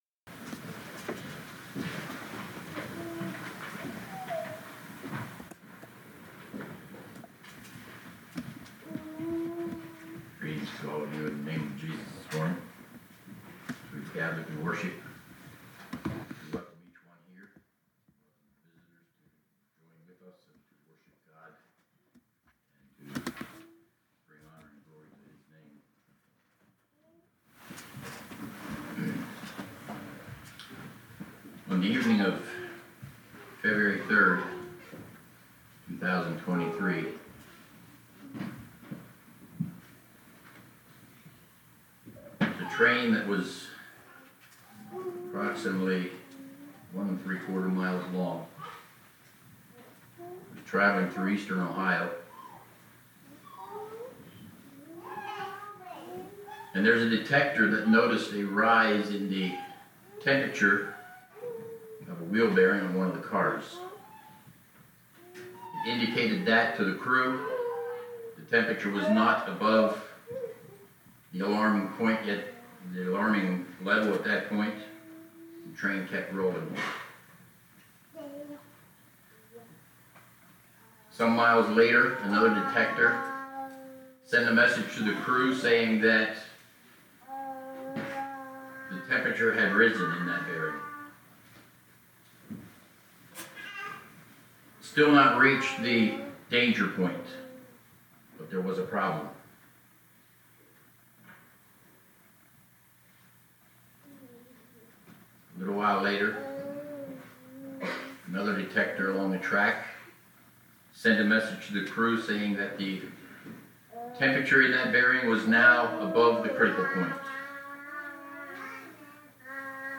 Congregation: Leola